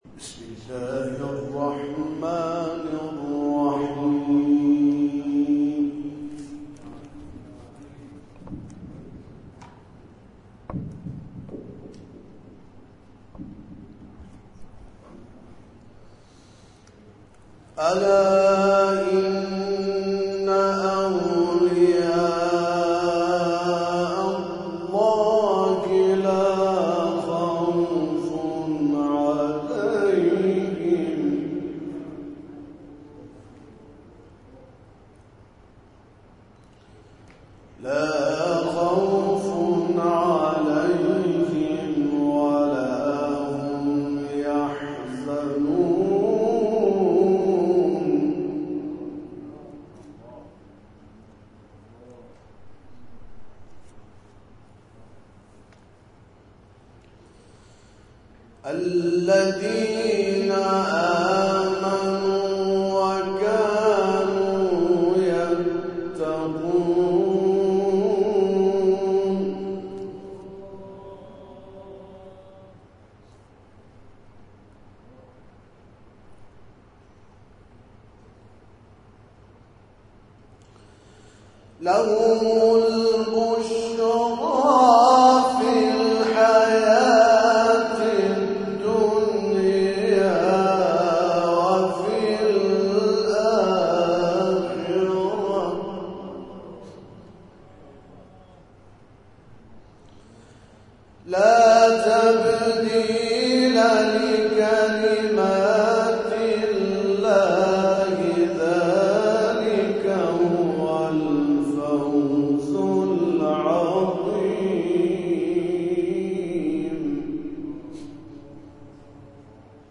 جزءخوانی با چاشنی تفسیر قرآن + صوت و عکس